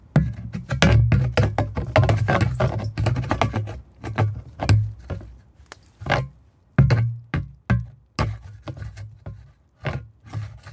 随机的 "塑料气体容器放在人行道上是空的
描述：塑料气体容器放下路面empty.wav
Tag: 路面 向下 塑料 气体 容器